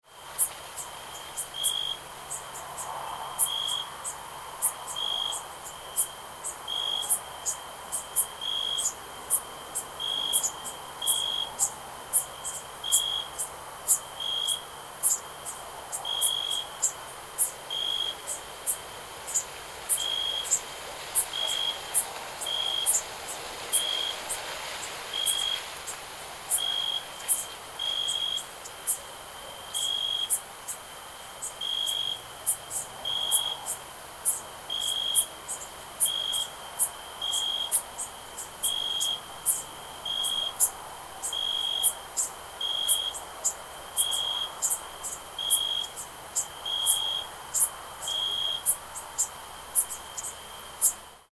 Cricket Chirping At Night Sound Effect
Enjoy the relaxing natural sound of a summer evening. Insects chirp gently while a soft breeze rustles through the leaves.
Cricket-chirping-at-night-sound-effect.mp3